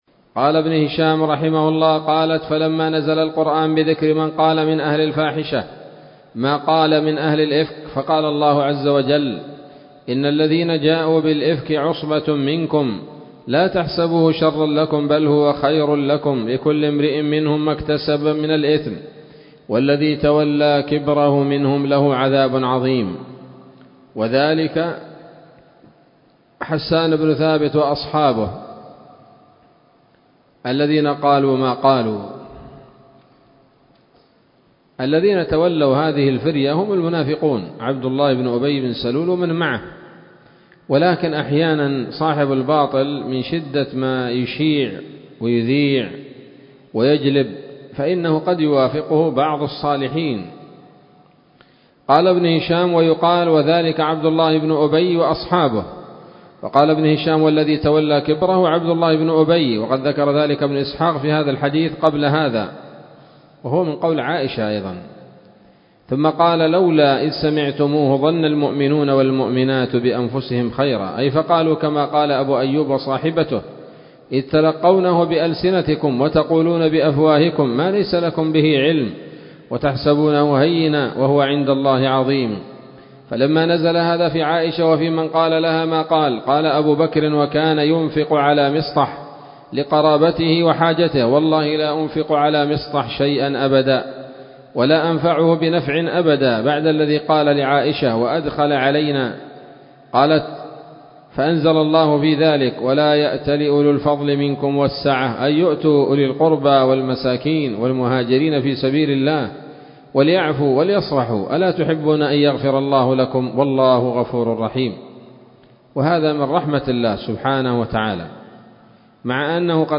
الدرس السابع والعشرون بعد المائتين من التعليق على كتاب السيرة النبوية لابن هشام